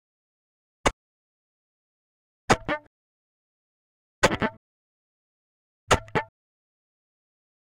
The recording was made with a violin with octave strings, so it sounds more like a cello..... however (there's always that however)
the first sound - is very short and 'sticky' bass notes. Most of the time, I'm playing G, G#, A, A. You'll easily be able to hear this in the recording.
I am using the very bottom of the bow and hitting the strings with a very, very short bow stroke, that is percussive in nature.